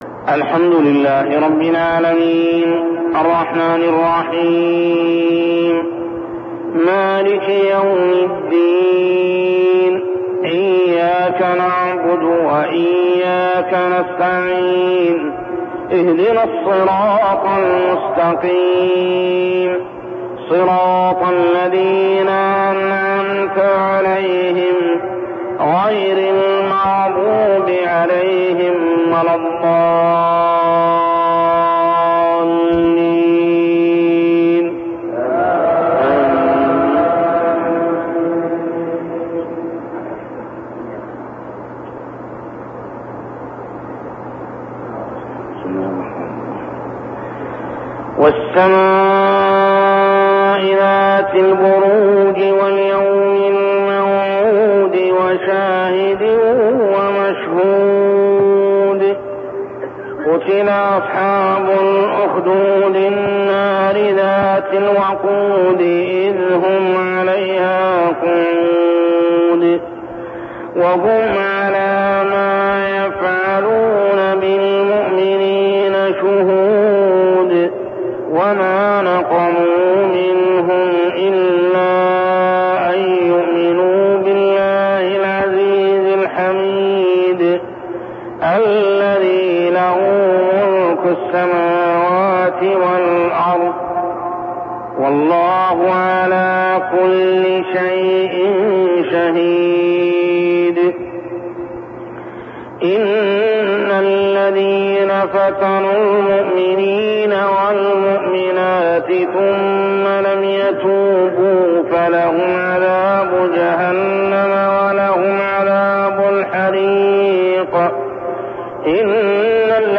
تلاوة من صلاة الفجر لسورتي البروج و الطارق كاملة عام 1402هـ | Fajr prayer Surah AL-BURUJ and Al-Tariq > 1402 🕋 > الفروض - تلاوات الحرمين